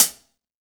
BR Hat Cl 1.WAV